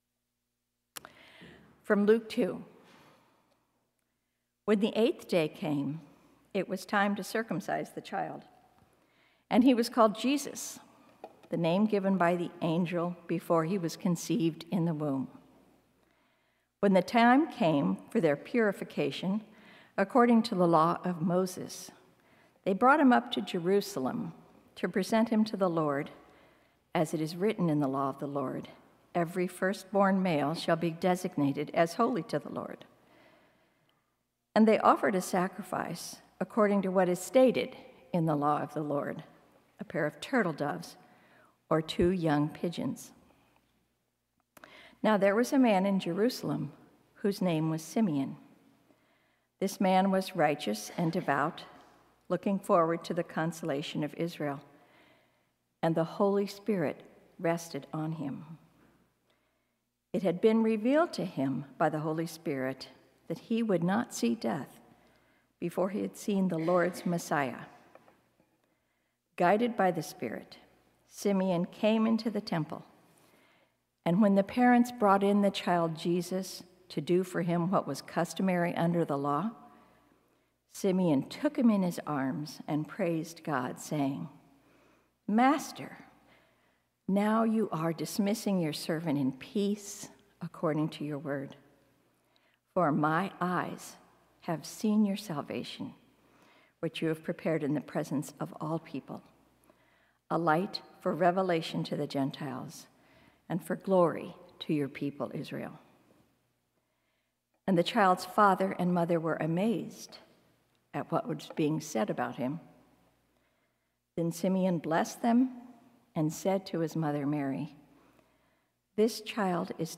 Sermons | Washington Community Fellowship